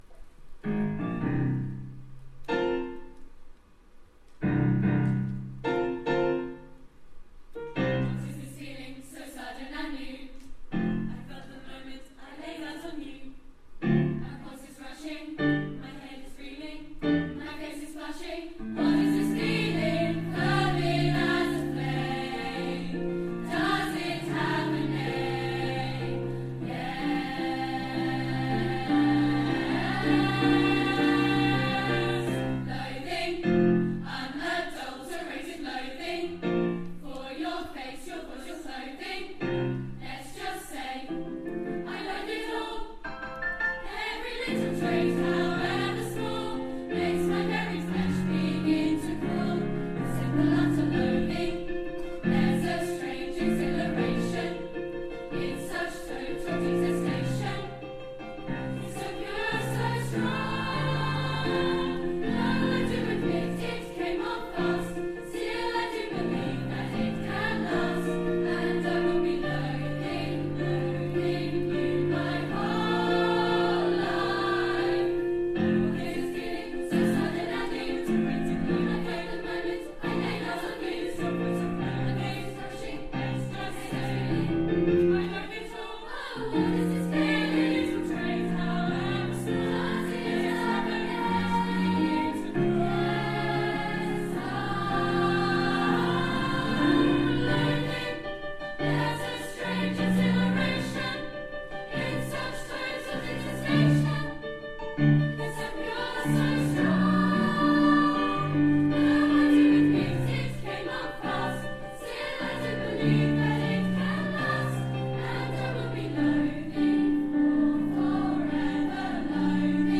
Autumn Concert 2017 second half